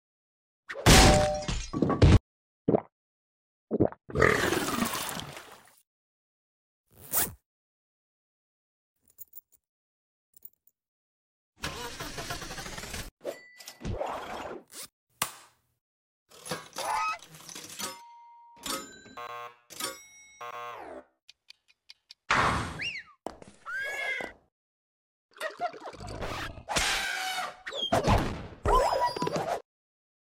⚠TW: Vomit sfx at 0:03 sound effects free download